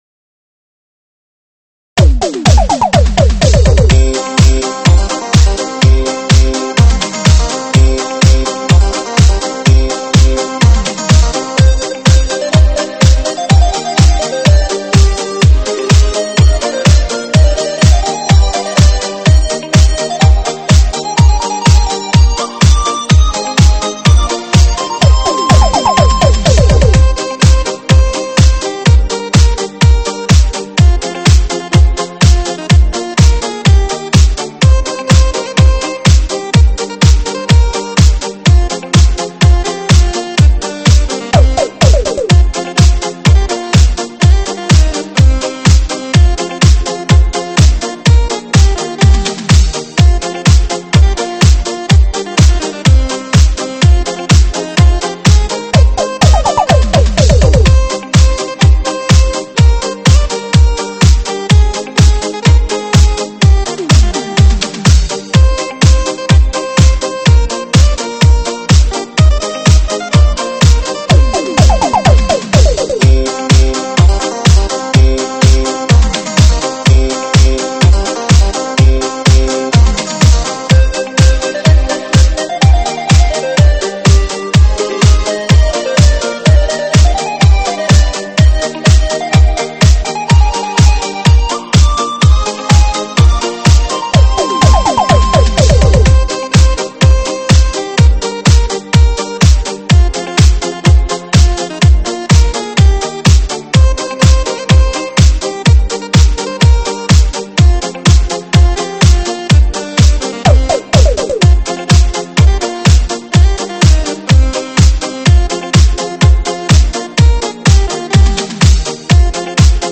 舞曲类别：电子琴